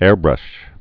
(ârbrŭsh)